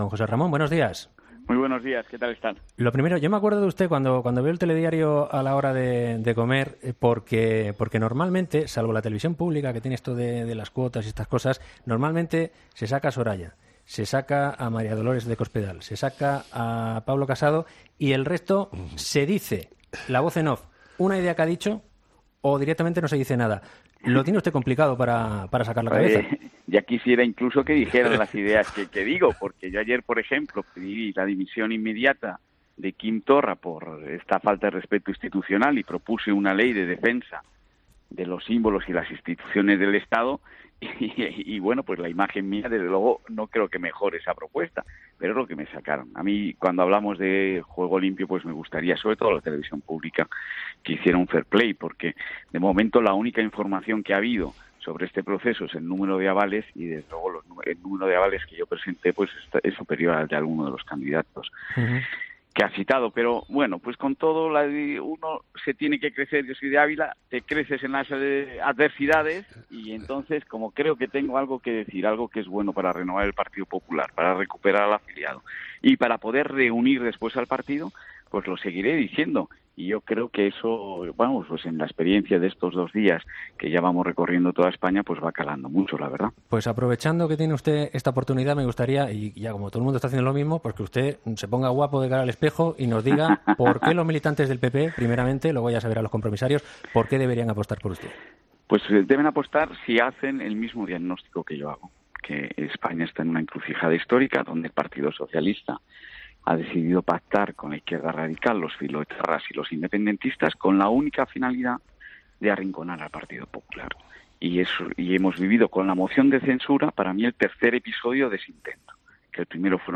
PINCHA AQUI PARA ESCUCHAR LA ENTREVISTA COMPLETA En este sentido asegura que hay que hacer políticas modernas pero con los valores clásicos del liberalism o, conservadurismo y del humanismo cristiano" .